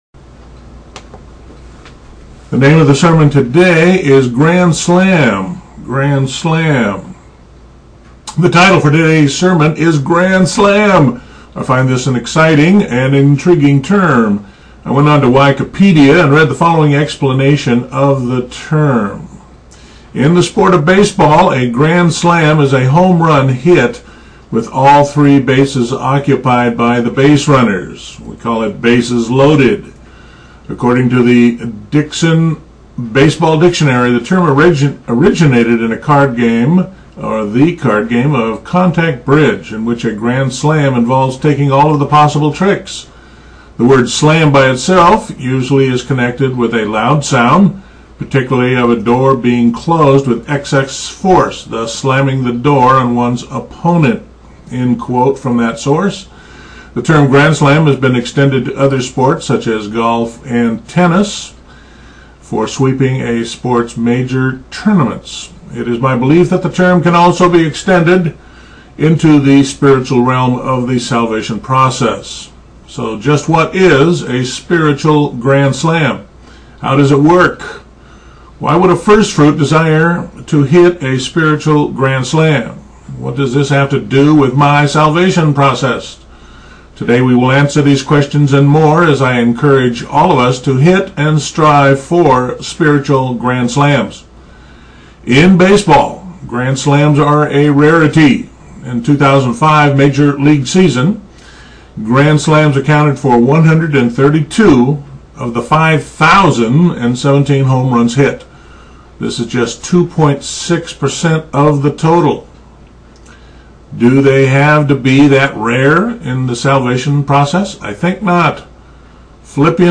Sermon: Grand Slam
A sermon about failure and success at the spiritual level.